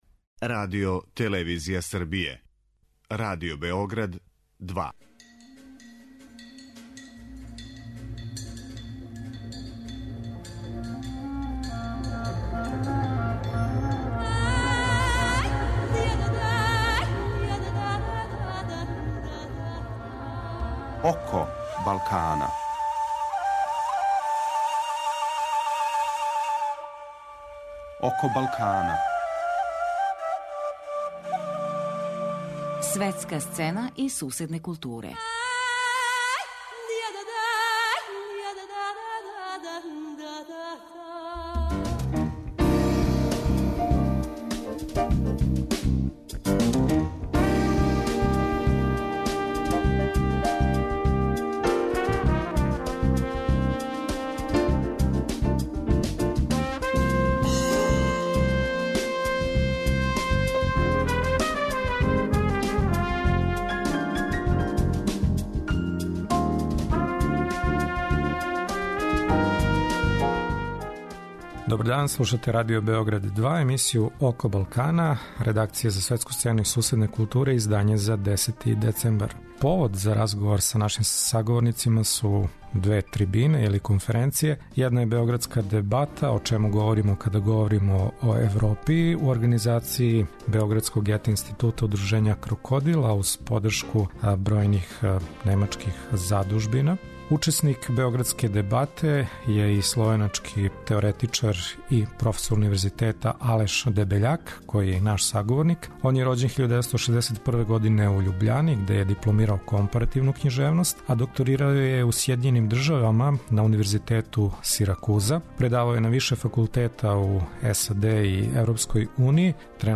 Први саговорник нам је Алеш Дебељак, словеначки универзитетски професор, есејиста и песник.